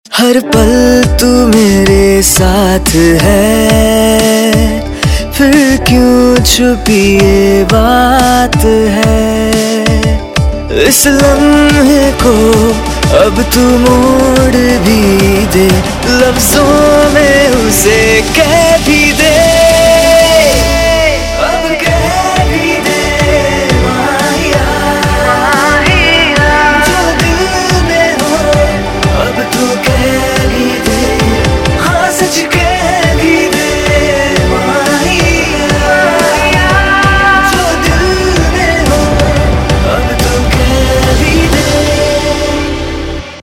Indian POP Ringtones